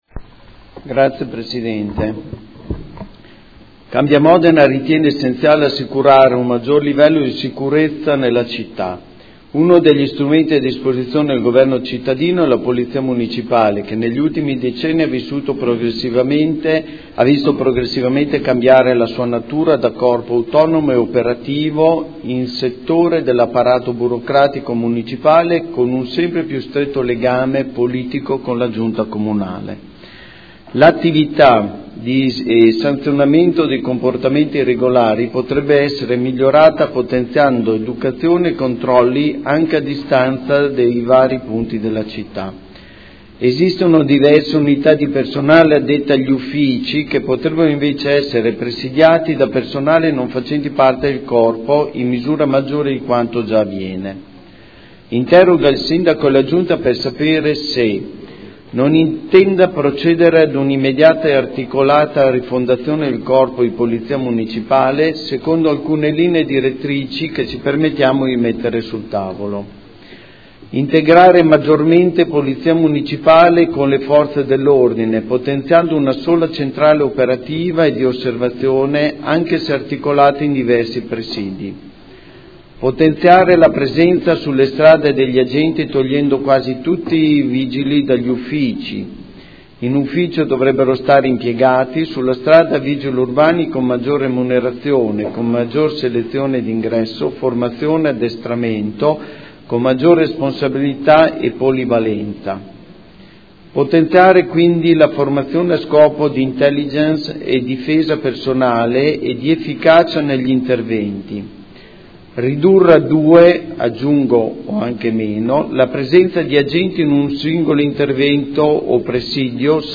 Audio Consiglio Comunale